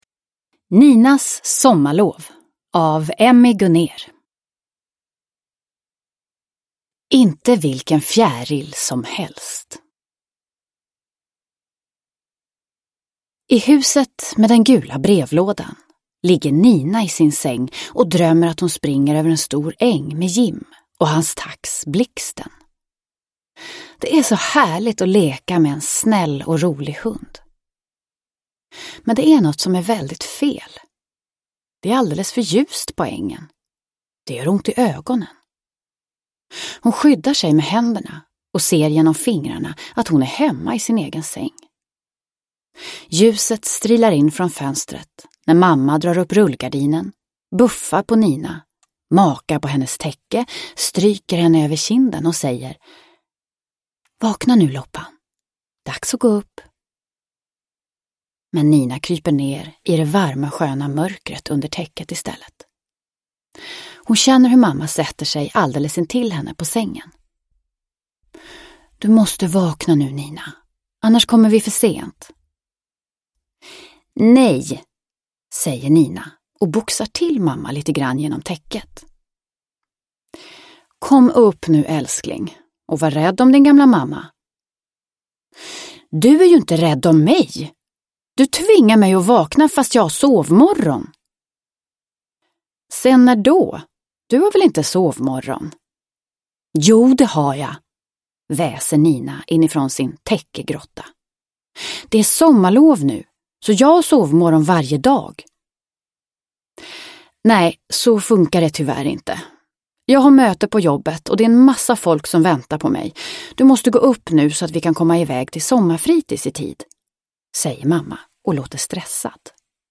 Ninas sommarlov – Ljudbok